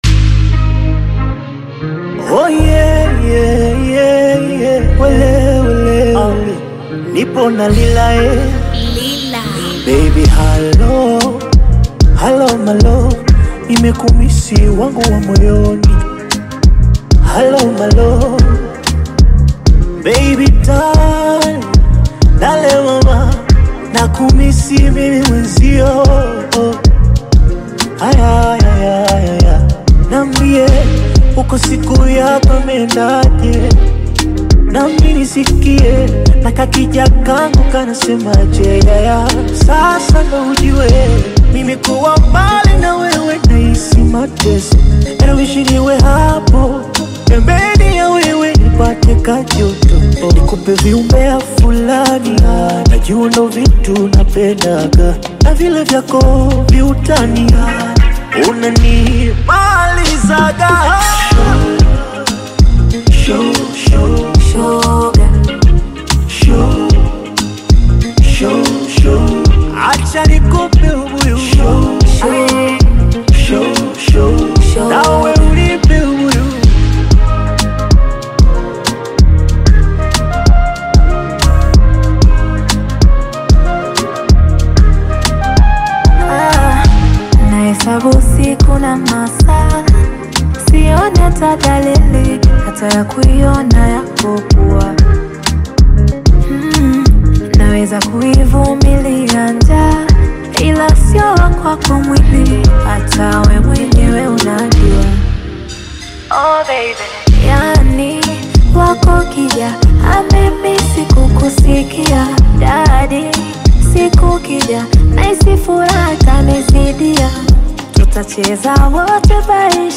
Bongo Flava music track
Bongo Flava You may also like